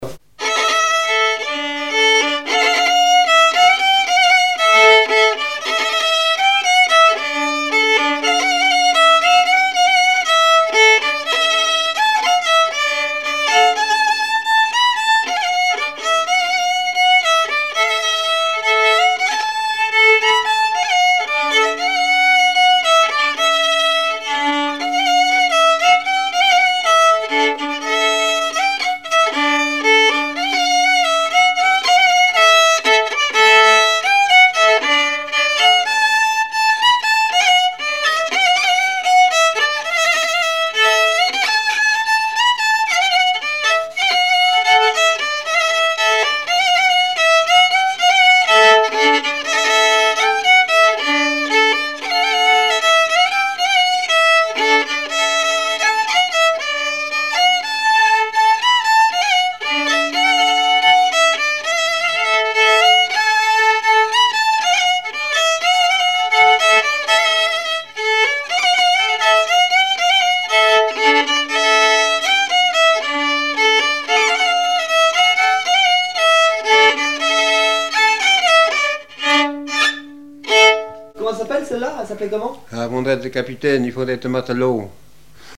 danse : marche
Genre strophique
Activité du violoneux
Pièce musicale inédite